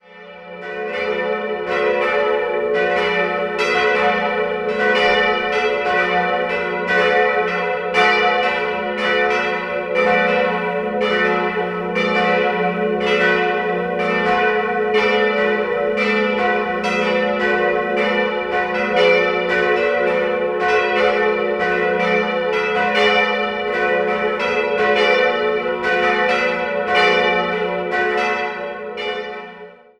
Jahrhundert stammt, ist das übrige Gotteshaus ein neubarocker Bau aus dem Jahr 1910. 3-stimmiges Geläut: fis'-a'-h' Die Glocken wurden 1950 von Karl Czudnochowsky in Erding gegossen.